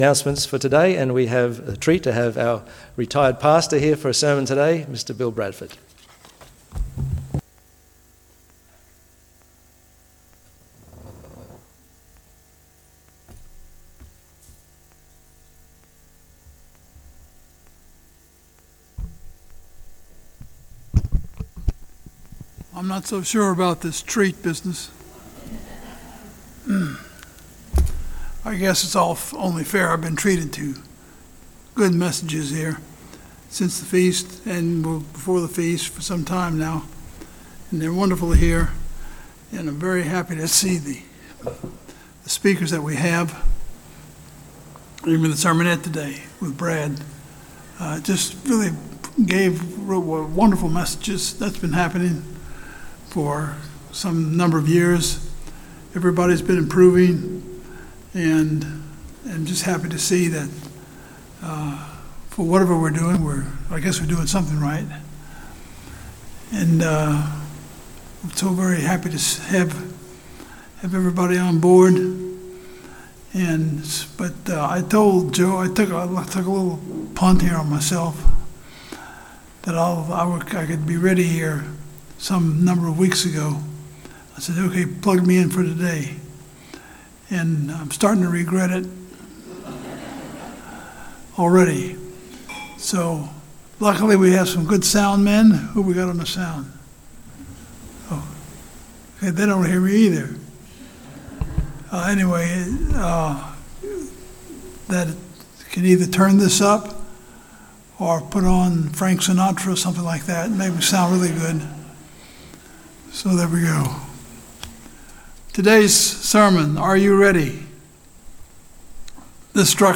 Given in Brisbane